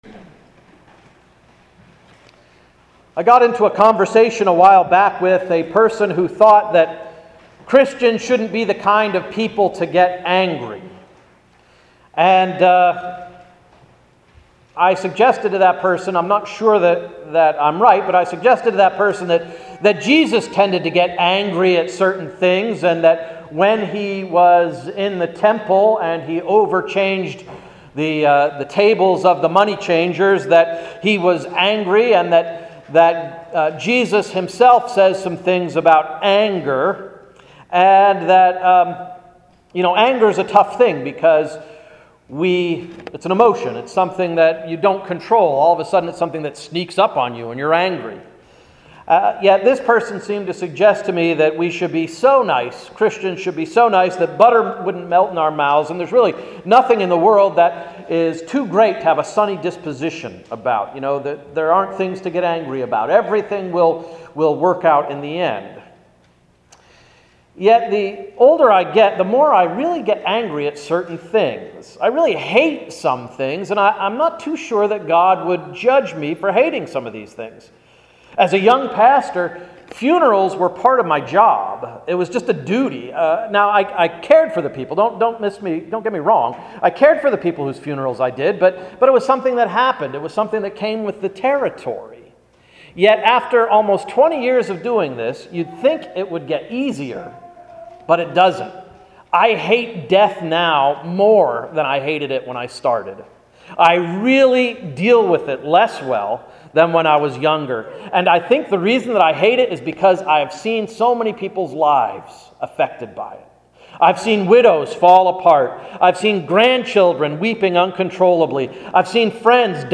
Easter Sunday Sermon–“Christ is Risen, Indeed!”
easter-sunday.mp3